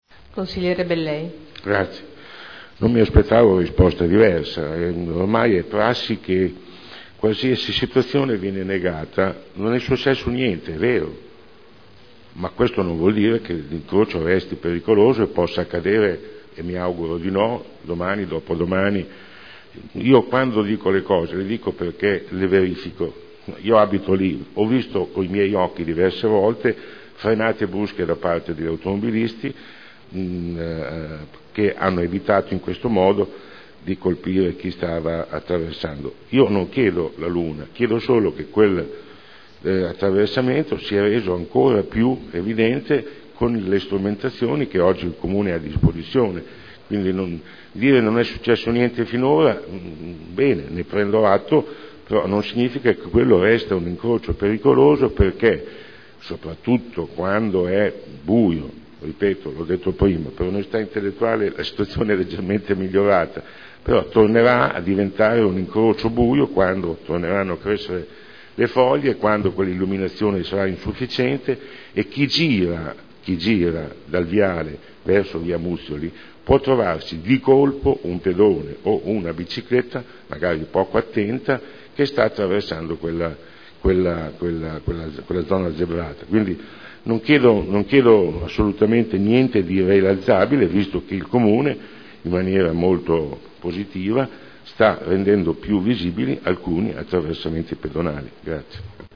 Sandro Bellei — Sito Audio Consiglio Comunale